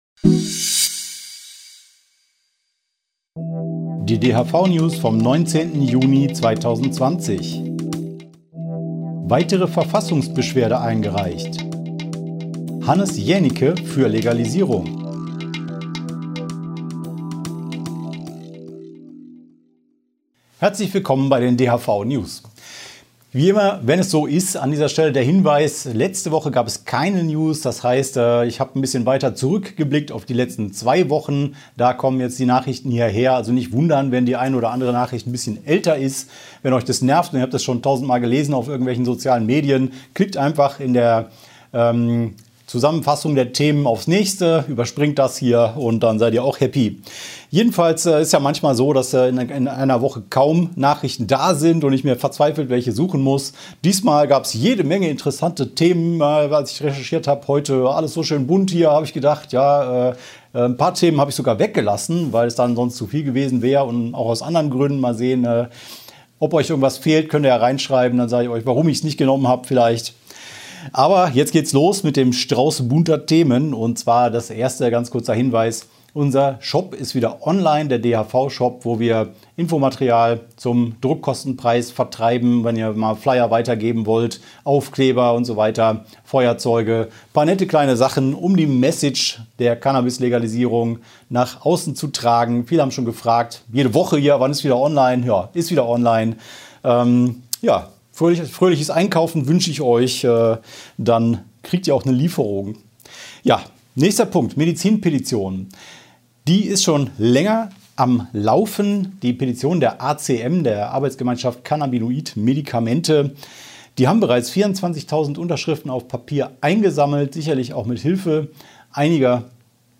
DHV-Video-News #253 Die Hanfverband-Videonews vom 19.06.2020 Die Sendung steht als Audio-Podcast am Ende dieser Nachricht zum download oder direkt hören zur Verfügung.